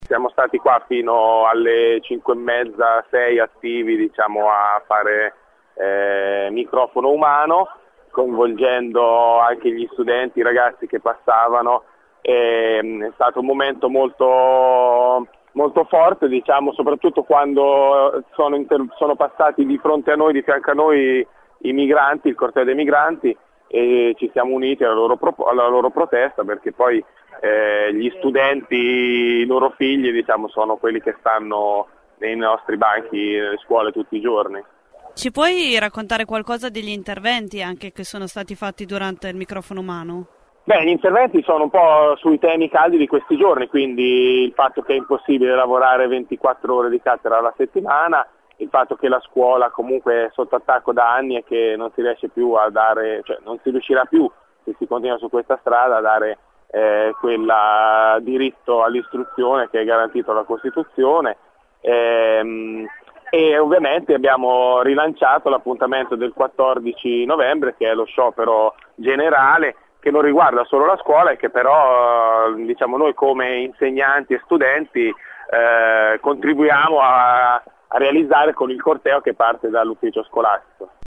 10 nov. – Un “megafono umano” per ripetere le ragioni della protesta degli insegnanti bolognesi. Alle 15 erano in trecento, secondo gli organizzatori, tra insegnanti di ruolo e precari, per il flash mob in piazza Nettuno.